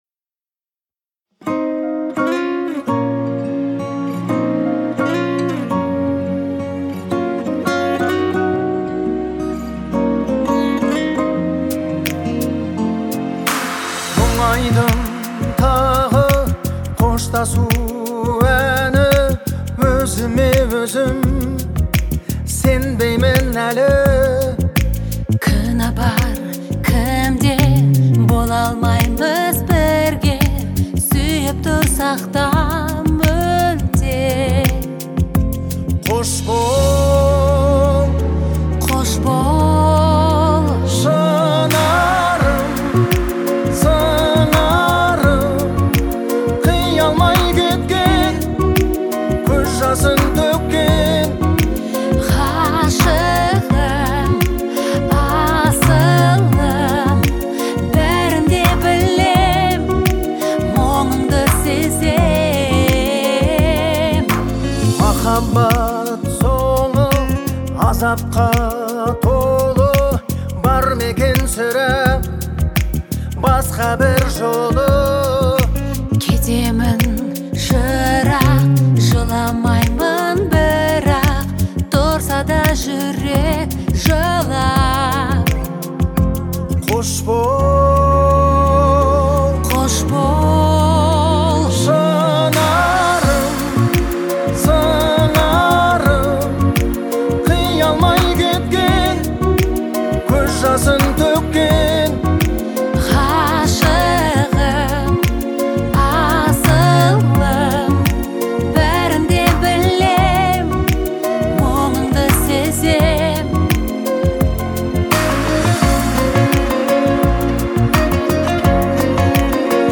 это трогательная песня в жанре поп